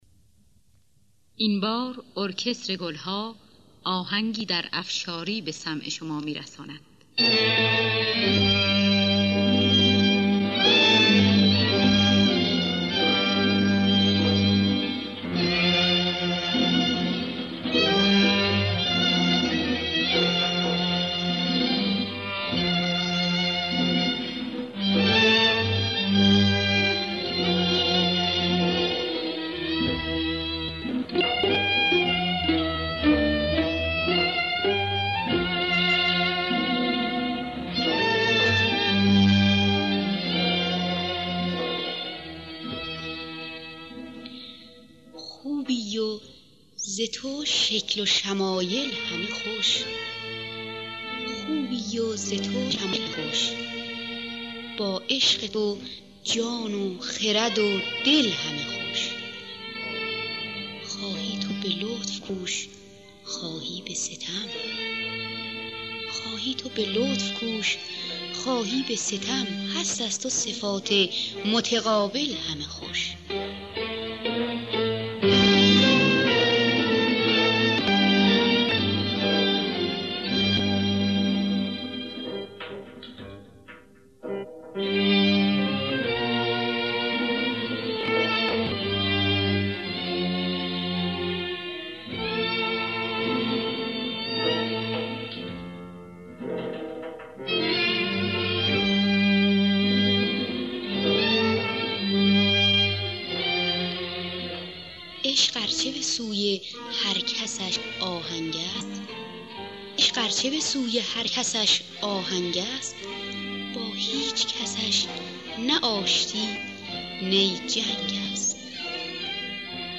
دانلود گلهای رنگارنگ ۲۲۵ با صدای مرضیه، اکبر گلپایگانی در دستگاه افشاری.